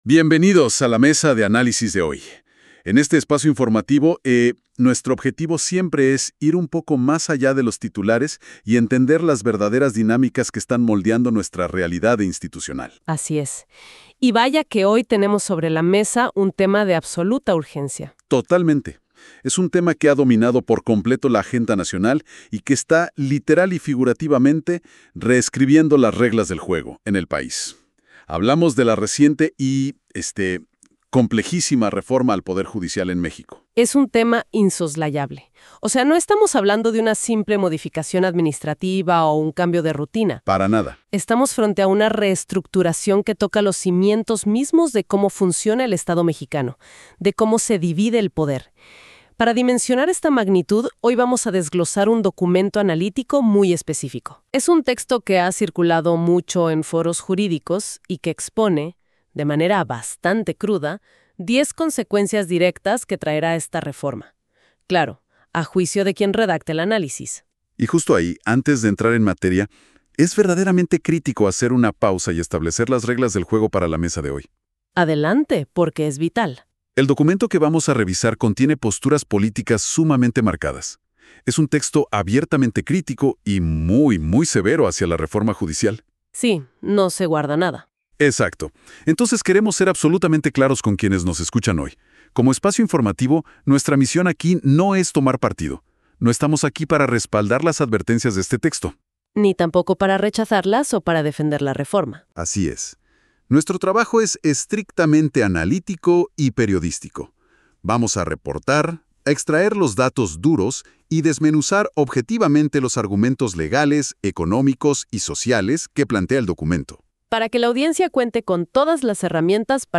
Escucha el análisis imparcial por expertos centrados en este artículo titulado 10 Consecuencias de la Reforma Judicial.